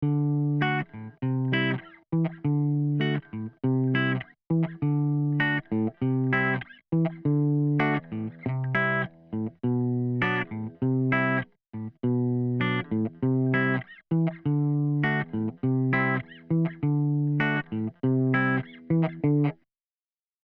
(bitte nicht auf die spielfehler achten. habe nur ganz schnell irgendwas eingezockt) Anhänge reverbtest.mp3 801,1 KB · Aufrufe: 271